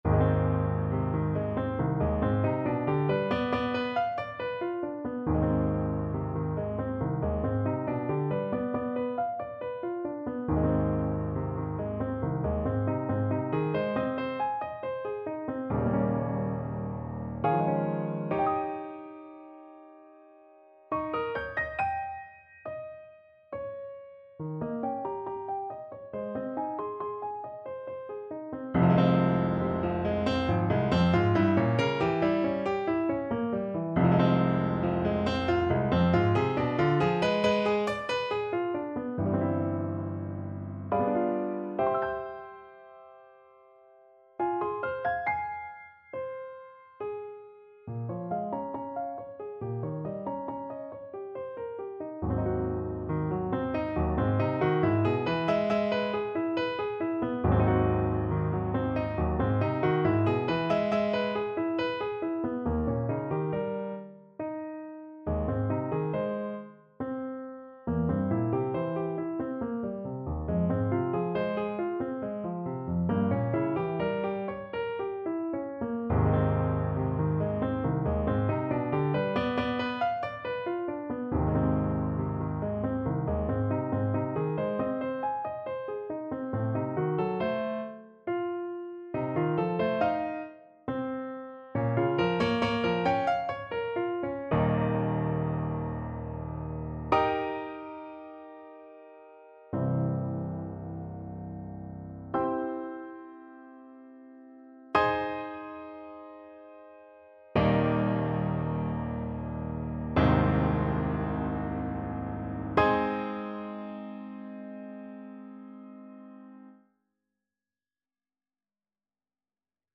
Play (or use space bar on your keyboard) Pause Music Playalong - Piano Accompaniment Playalong Band Accompaniment not yet available reset tempo print settings full screen
Ab major (Sounding Pitch) (View more Ab major Music for Trombone )
3/4 (View more 3/4 Music)
~ = 69 Large, soutenu